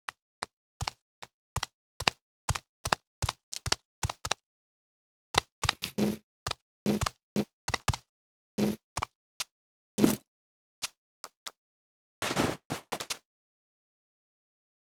Mais par contre, comme j'ai joué sur le pan des sons de pas, le souffle bouge de gauche à droite...
Alors effectivement ya plus de souffle.
Mais il aurait fallu que je te file le son du pas uniquement, parce que du coup ca a gratté dans les autres son, frottement du bout de bois tiré et celui du drap enlevé.